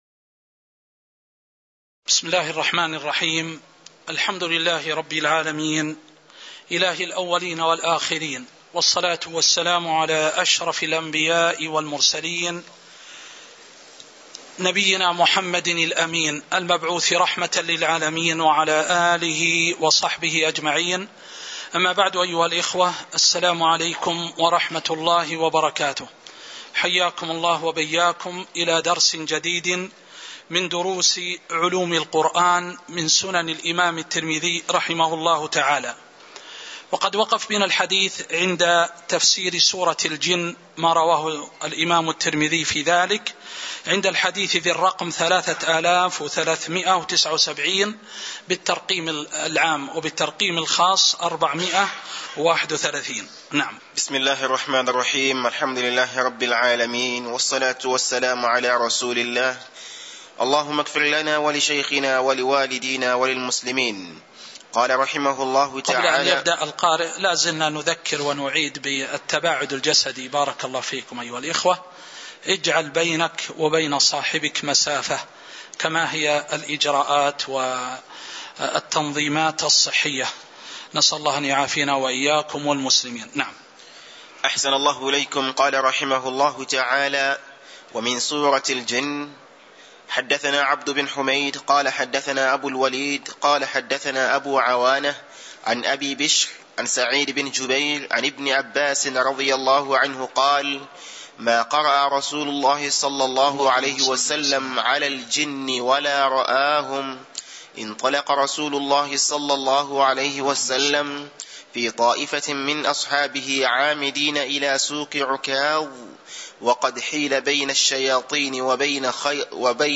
تاريخ النشر ٢ شعبان ١٤٤٣ هـ المكان: المسجد النبوي الشيخ